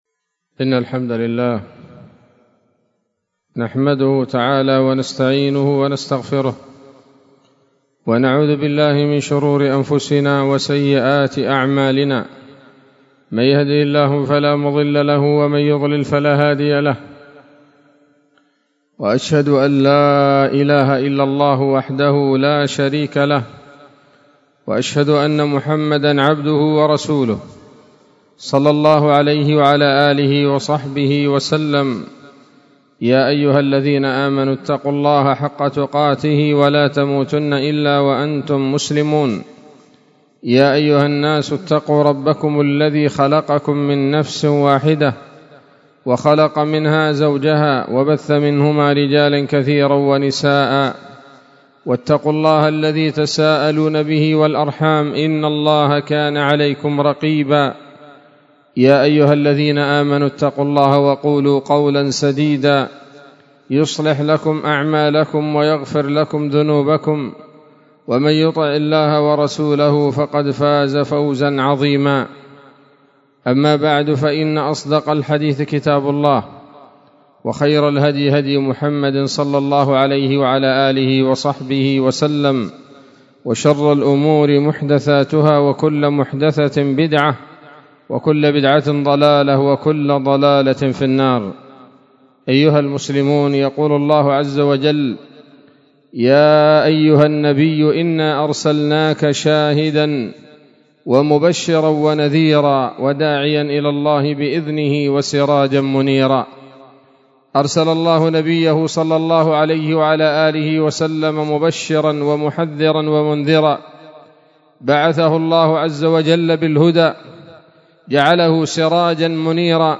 خطبة جمعة بعنوان: (( نصرة الرسول ﷺ بنصرة دينه )) 11 من شهر ذي القعدة 1443 هـ، دار الحديث السلفية بصلاح الدين